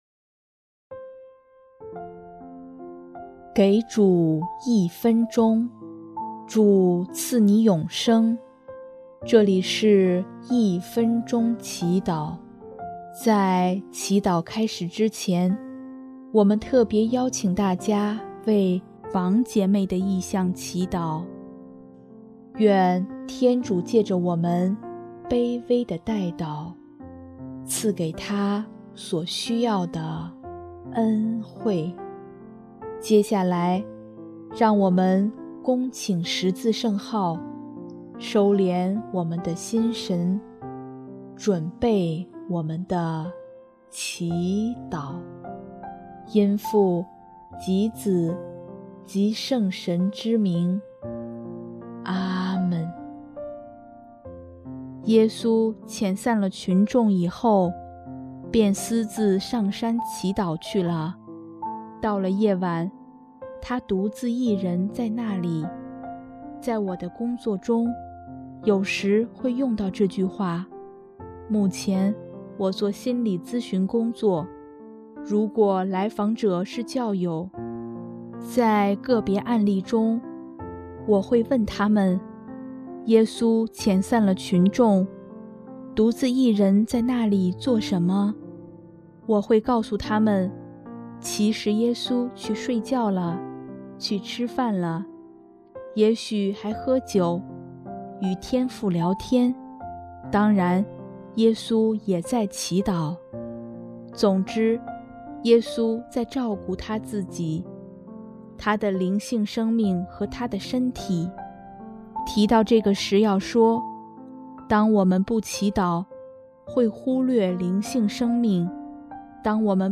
音乐：主日赞歌